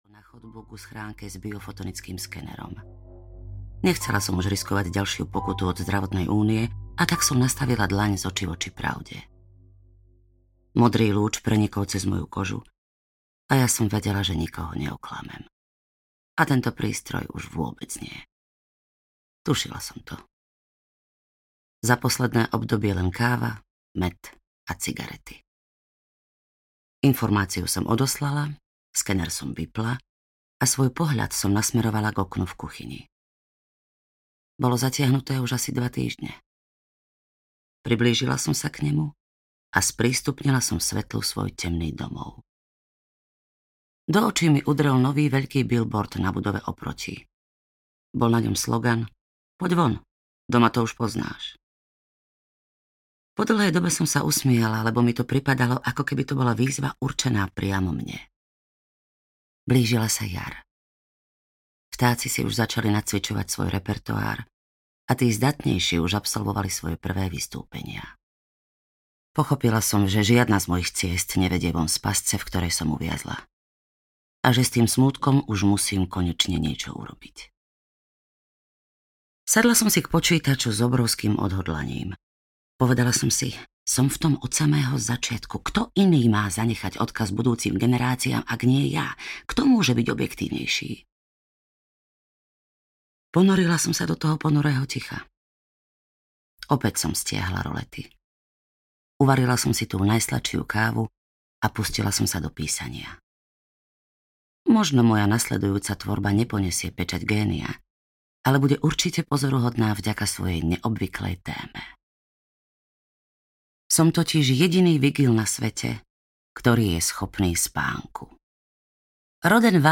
VIGIL audiokniha
Ukázka z knihy